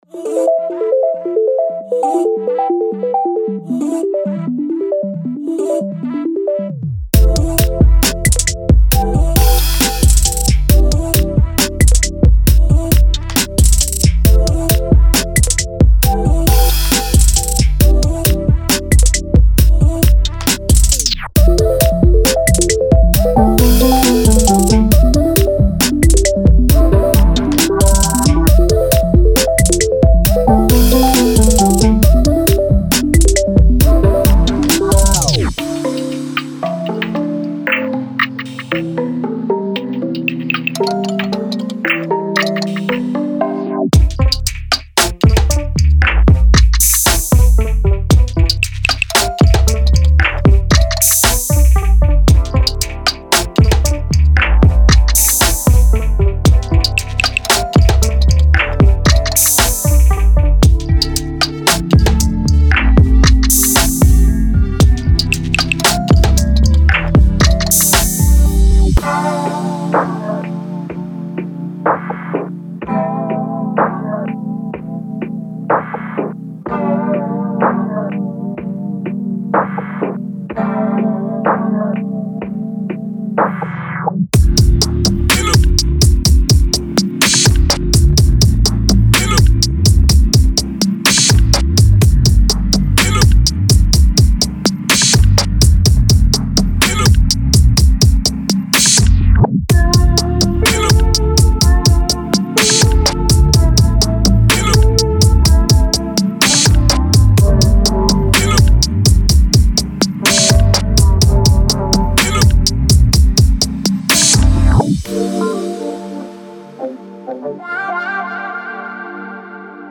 所有内容都经过精心混合和均衡，以实现最佳的声音平衡，同时为后续处理和最终的母带处理留出了足够的额外空间。